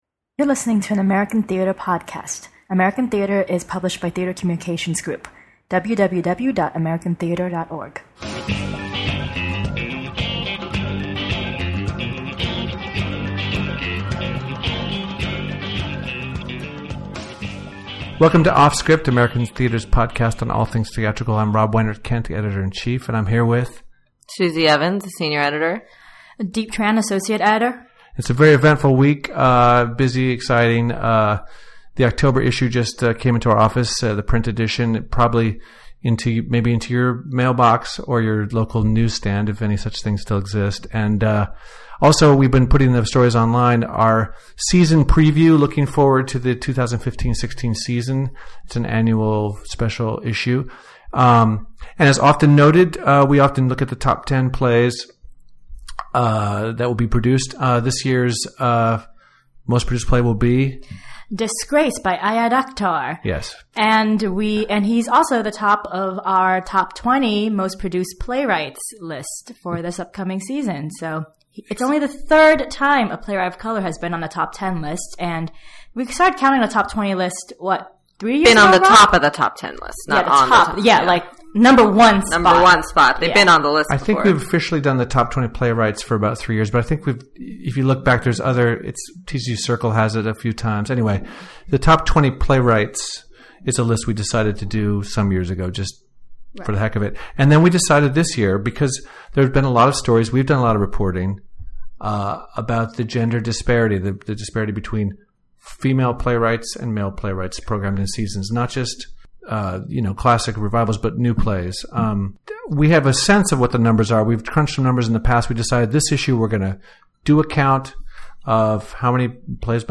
Every other week, the editors of American Theatre curate a free-ranging discussion about the lively arts in our Offscript podcast.
First up is Pulitzer-winning playwright Ayad Akhtar, who came into the office to talk Disgraced, the most-produced play for 2015–16.